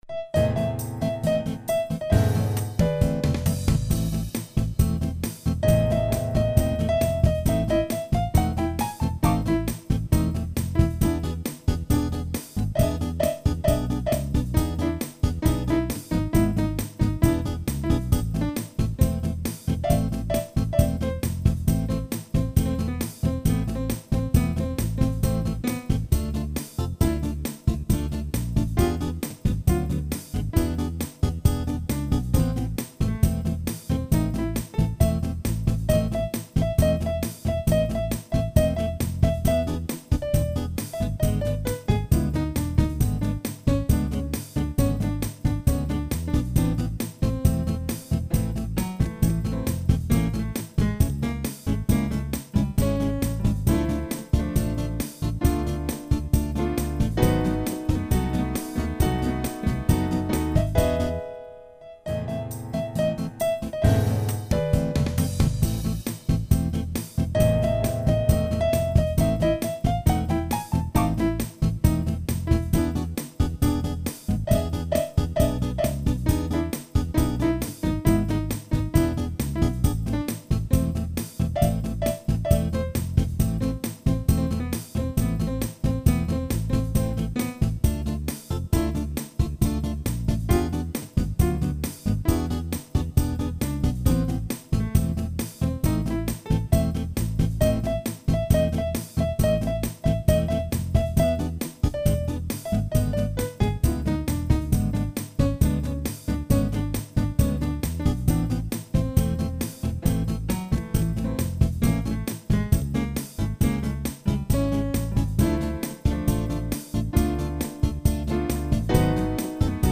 • Жанр: Электронная
инструментовка- имипровиз